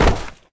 hit_hard1.ogg